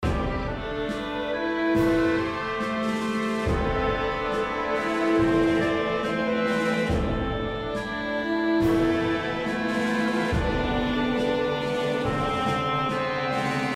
Orchestral Loop